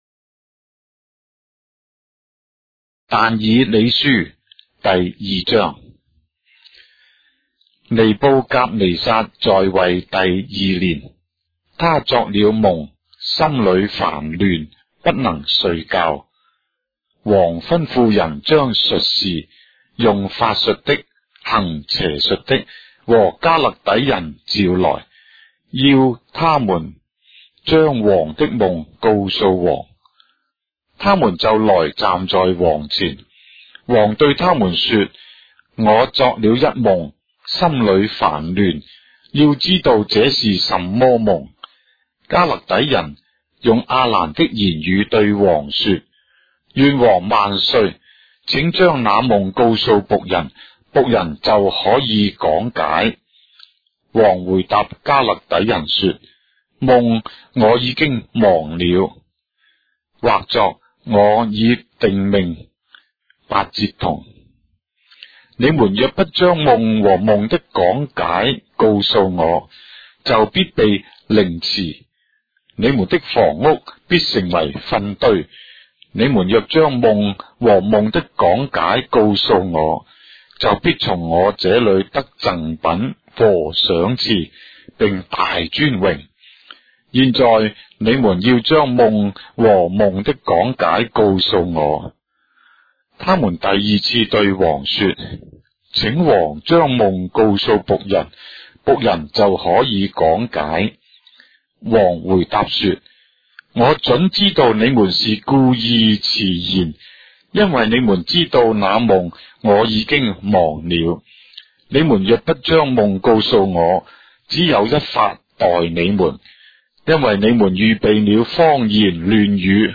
章的聖經在中國的語言，音頻旁白- Daniel, chapter 2 of the Holy Bible in Traditional Chinese